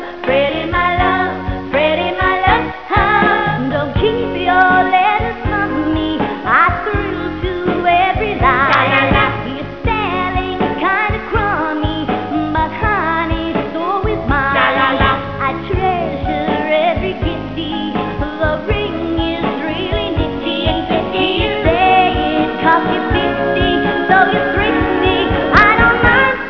Live Sound Clips from "Grease'